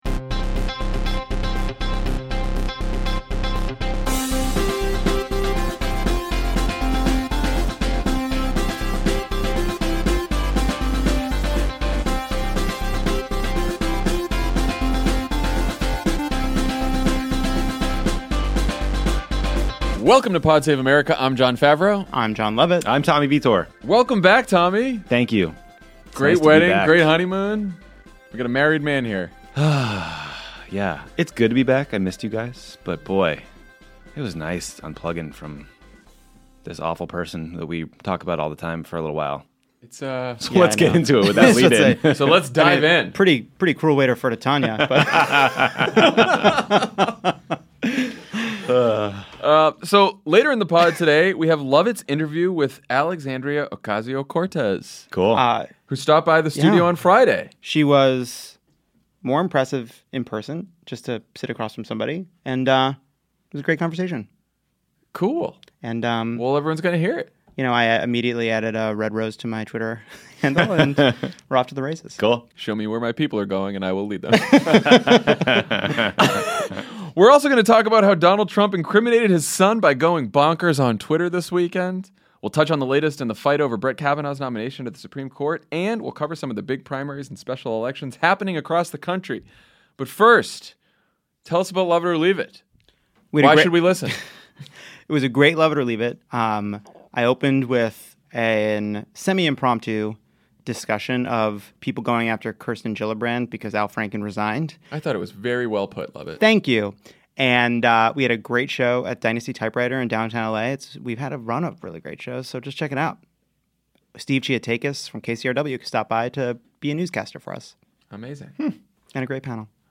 The President potentially incriminates his son on Twitter, Republicans try to rush the Kavanaugh confirmation, and more Democratic incumbents face progressive challengers. Then Alexandria Ocasio-Cortez talks to Jon Lovett about her big upset and the future of the Democratic Party.